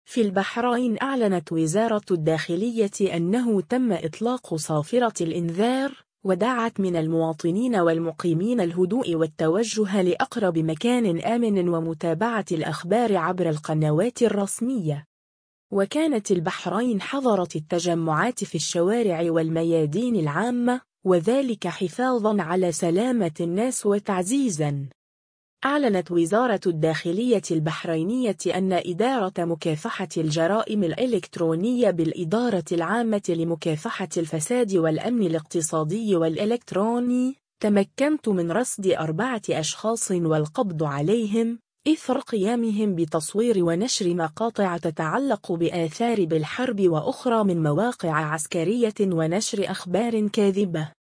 عاجل : إطلاق صفارات الإنذار في البحرين